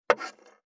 559まな板の上,包丁,ナイフ,調理音,料理,
効果音